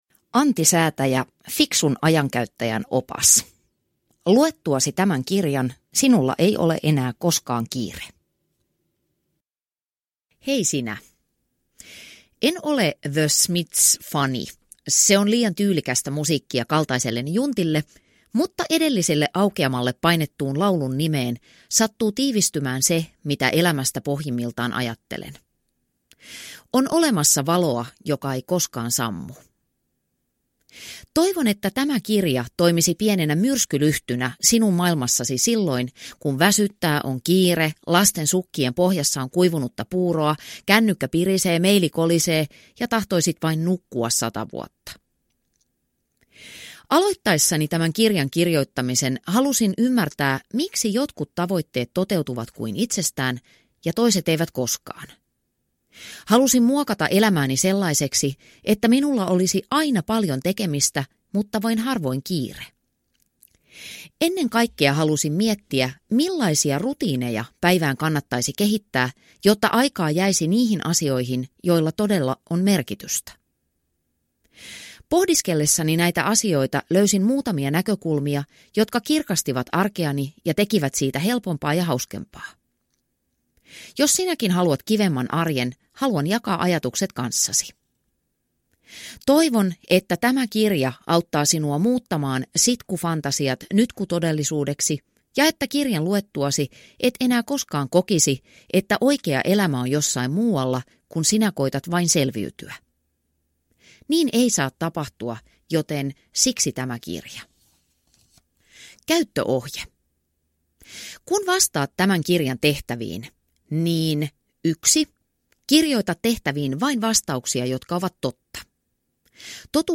Antisäätäjä – Ljudbok – Laddas ner